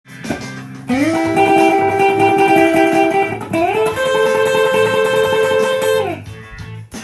エレキギターで弾けるAmで【盛り上がり確定フレーズ集】
全てAmキーの曲で使えるフレーズになります。
和音系のトレモロ奏法を使ったものです。